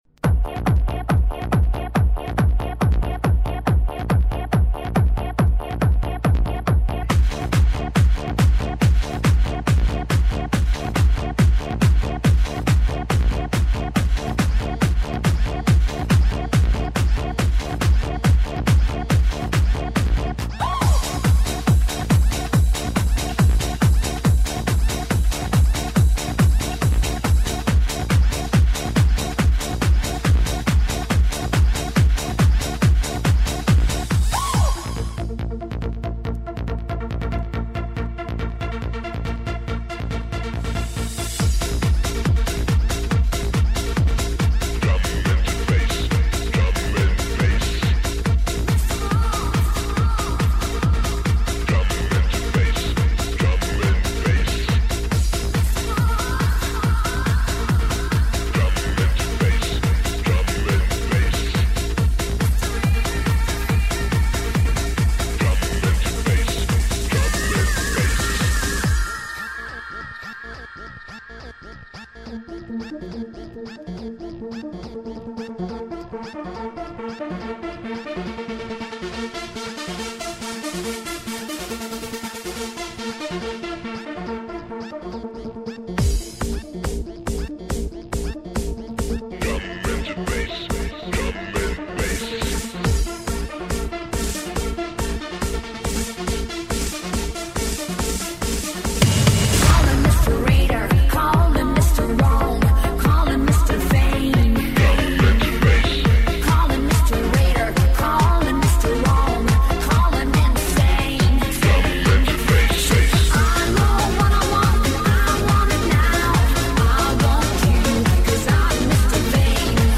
Žánr: Pop
Cover remixes